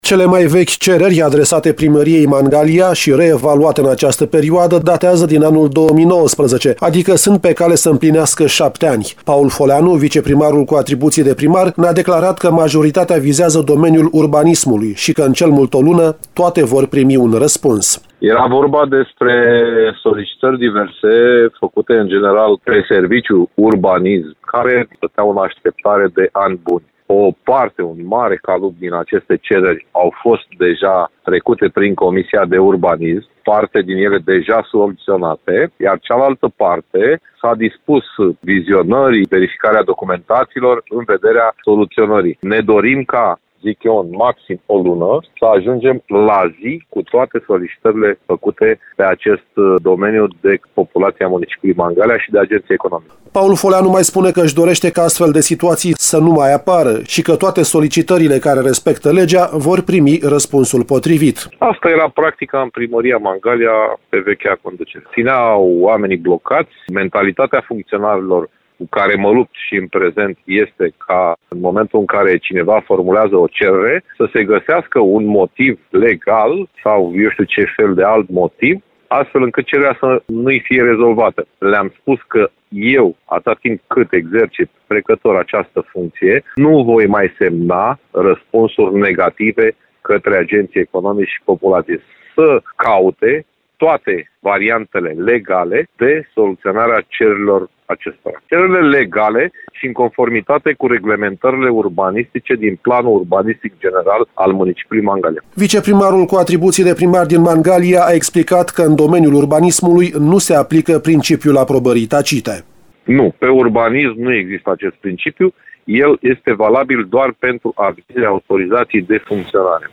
Autoritățile locale din Mangalia lucrează, în această perioadă, la reevaluarea a sute de cereri, depuse de agenți economici și de cetățeni, care nu au primit răspuns sau care au fost blocate, în ultimii ani, din diverse motive, spune Paul Foleanu, viceprimarul cu atribuții de primar al municipiului.